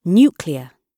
Nuclear /ˈnjuːkliə(r)/
nuclear__gb_2.mp3